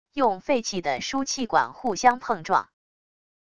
用废弃的输气管互相碰撞wav音频